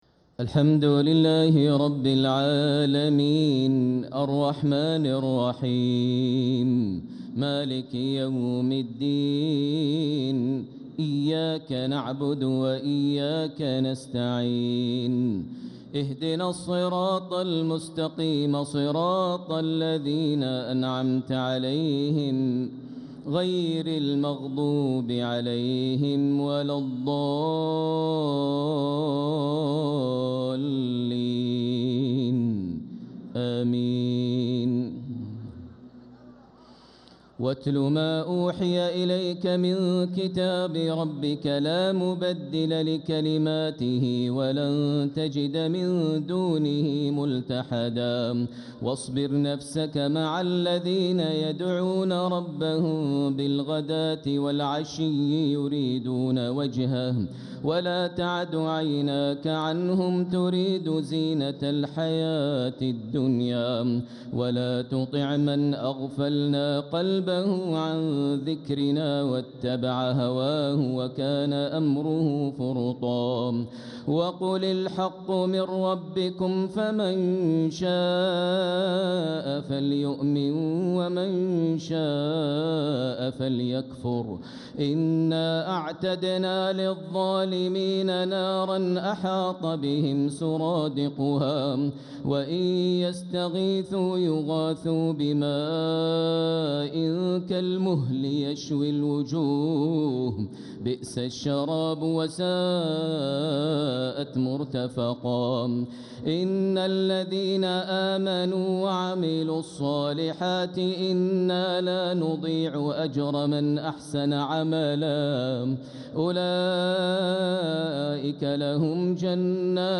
تراويح ليلة 20 رمضان 1446هـ من سورة الكهف (27-82) | Taraweeh 20th night Ramadan1446H Surah Al-Kahf > تراويح الحرم المكي عام 1446 🕋 > التراويح - تلاوات الحرمين